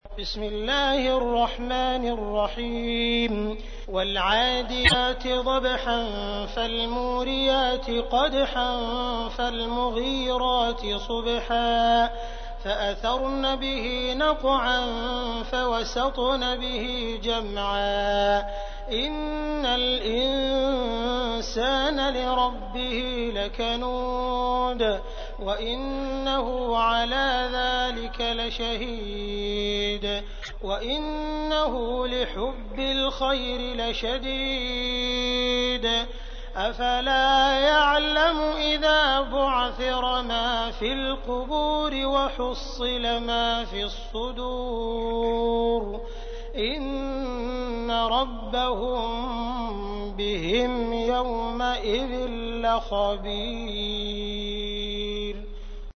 تحميل : 100. سورة العاديات / القارئ عبد الرحمن السديس / القرآن الكريم / موقع يا حسين